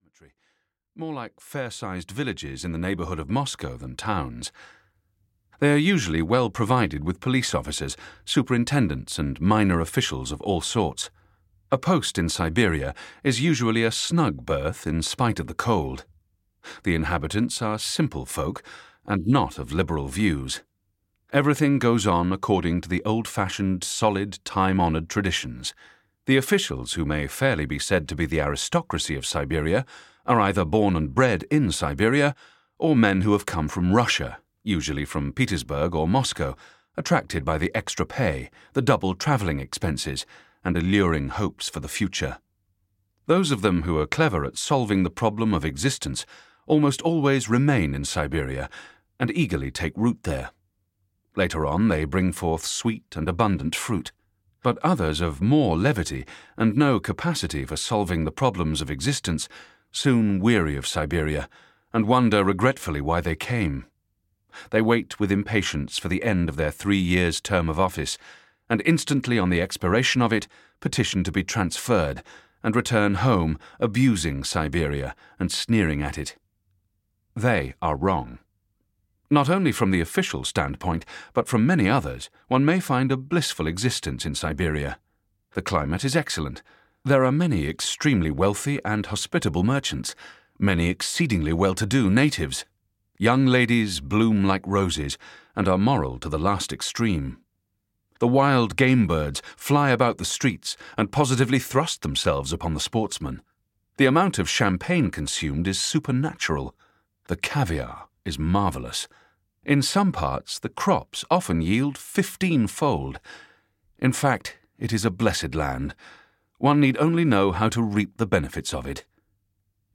The House of the Dead (EN) audiokniha
Ukázka z knihy